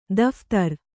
♪ daphtar